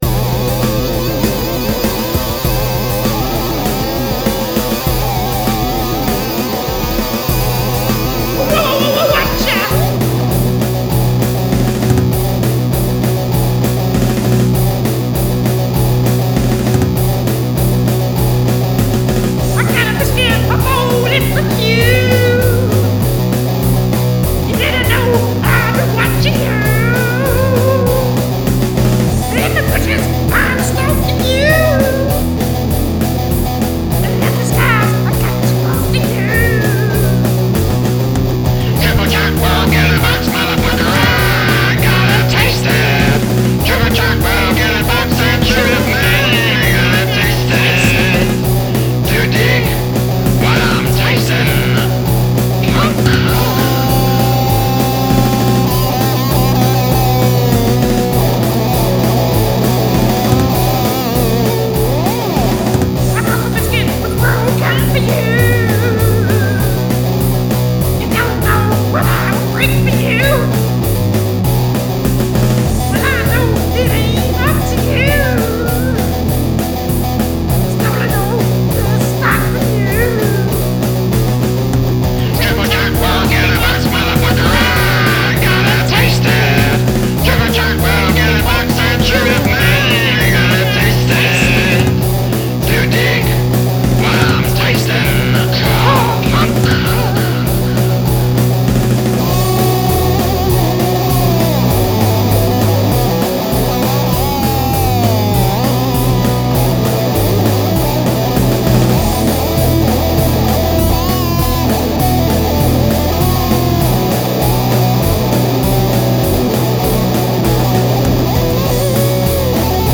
Shucks, this is a heavy one!